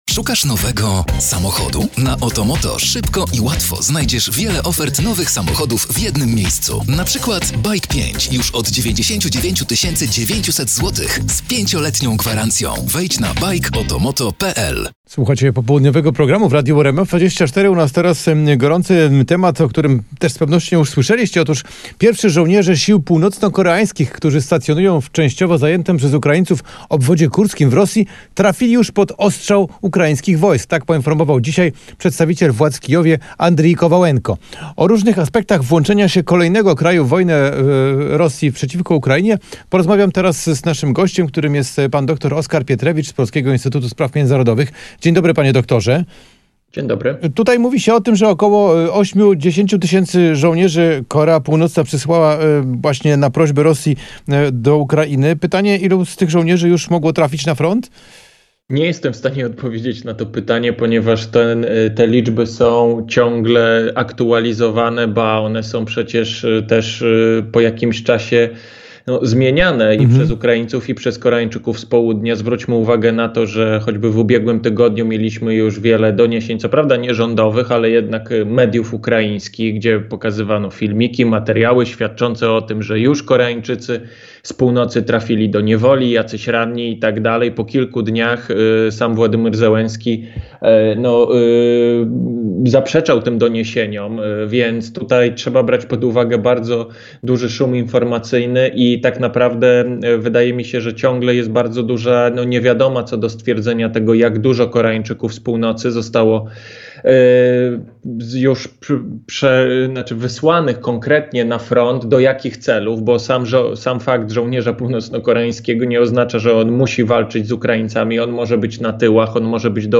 18:00 Fakty i Popołudniowa rozmowa w RMF FM - 04.11.2024